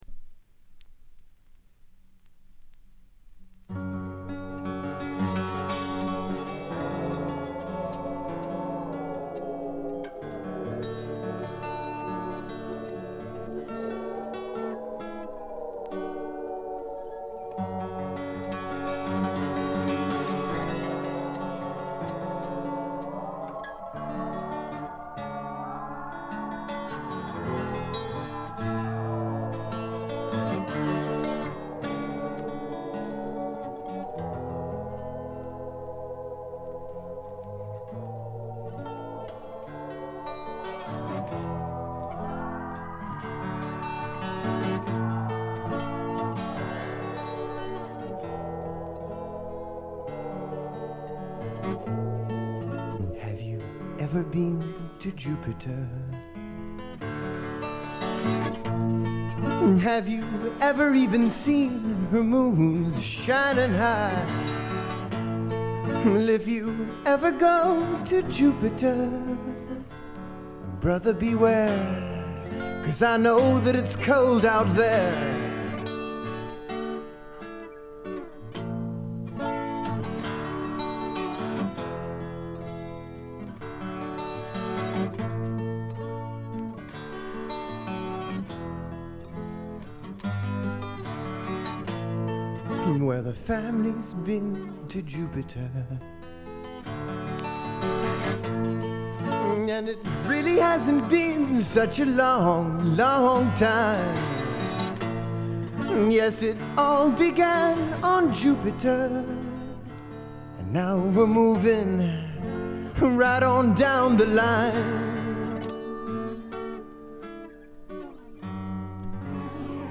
いうまでもなく米国産アシッド・フォークの超有名レア盤にして永遠の至宝です。
異常に美しいアコースティック・ギターの響きとスペイシーなシンセが共鳴する異空間。
試聴曲は現品からの取り込み音源です。